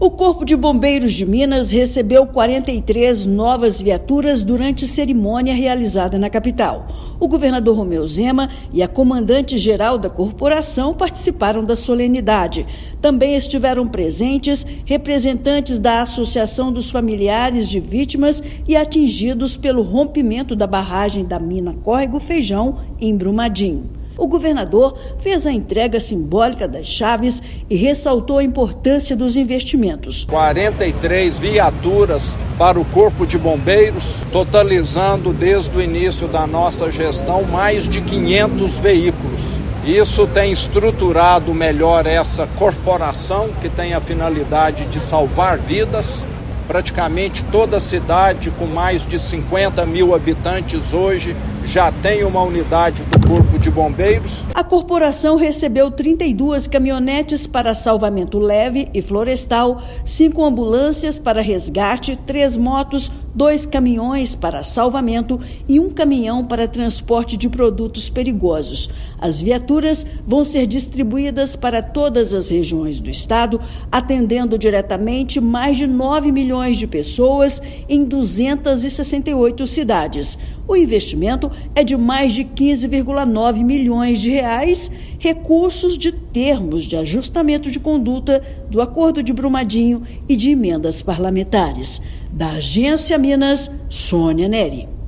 [RÁDIO] Governo de Minas entrega 43 viaturas ao Corpo de Bombeiros Militar
Reforço da frota incrementará o atendimento para mais de 9 milhões de pessoas em 268 cidades. Ouça matéria de rádio.